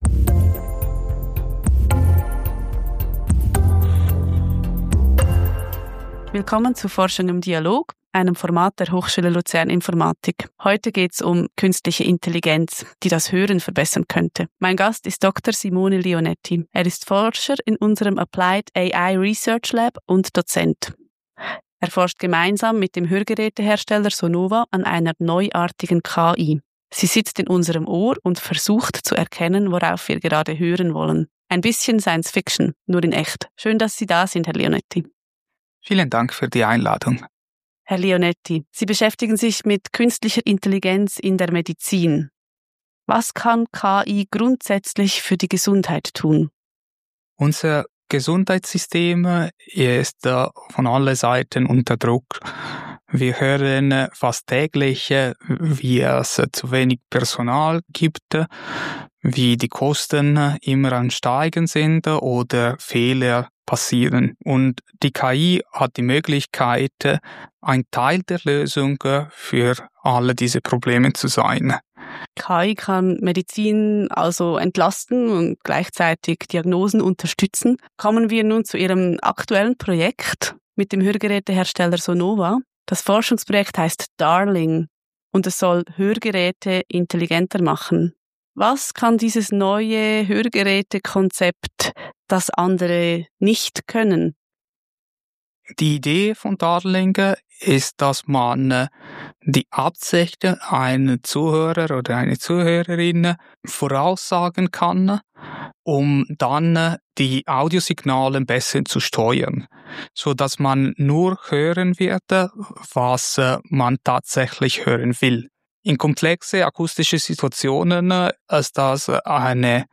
🎧 Das Gespräch gibt es auch als Audiointerview.